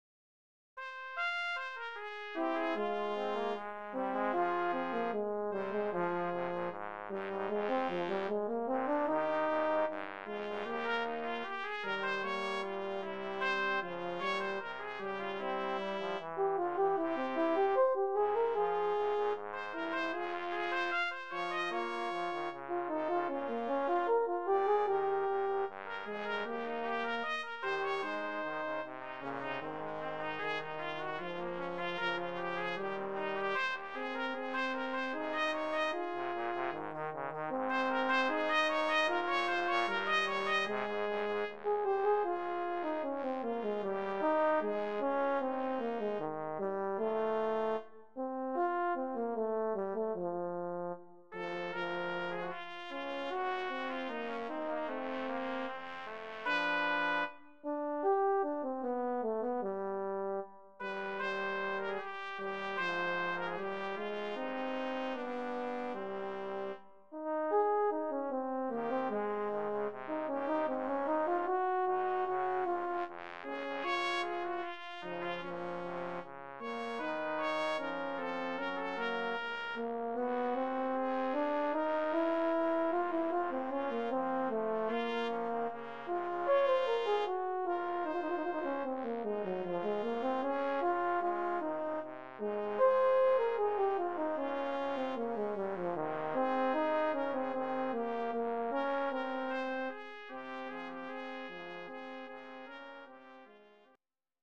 Voicing: Brass Trio